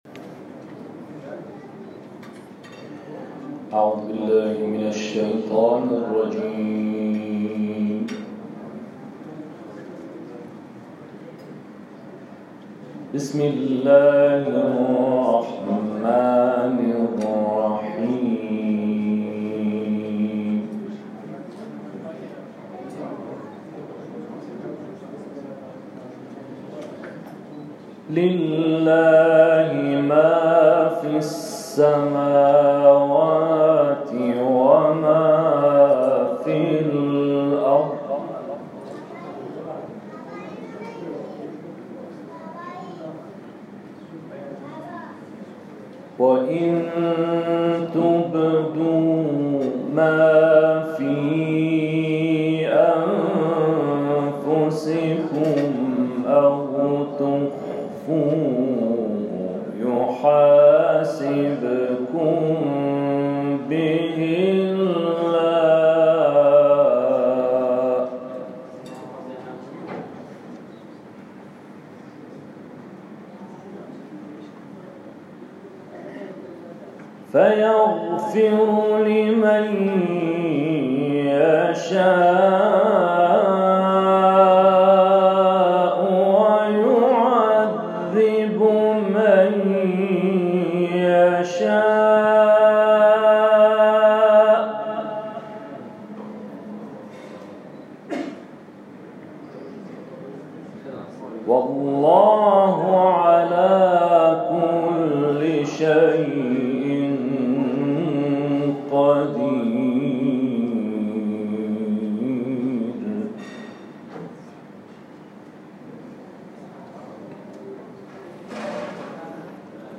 تلاوت جدید